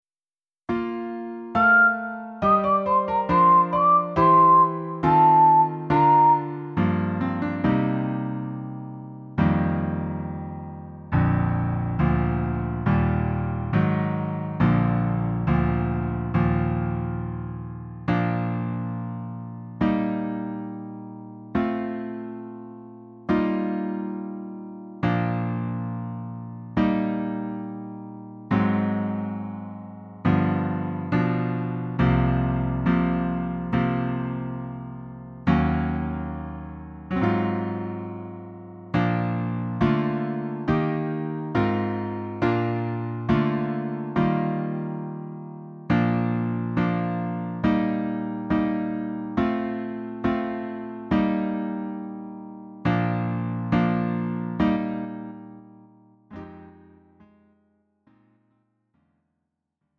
음정 -1키 4:08
장르 가요 구분 Lite MR